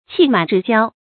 气满志骄 qì mǎn zhì jiāo
气满志骄发音